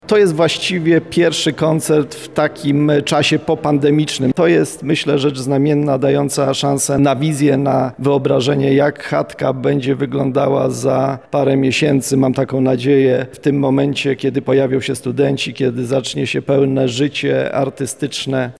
Z powrotu do koncertów i spotkań w Chatce cieszy się rektor UMCS, prof. Radosław Dobrowolski.
2.Rektor.mp3